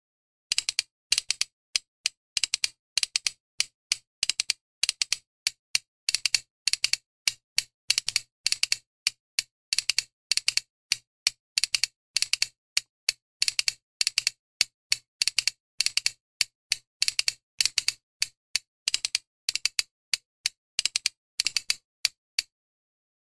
Enregistré avec cet instrument musical.
TXEPETXA; KASKAMELAN; INTXAURRA
Instruments de musique: TXEPETXA; KASKAMELAN; INTXAURRA Classification: Idiophones -> Frappés -> Indirectement Emplacement: Erakusketa biltegia; taldeak Explication de l'acquisition: Egina; Haurtzaroan Etxarri Aranazen erabiltzen genituenak bezalakoa.
Description: Intxaurraren azal-erdi bat da.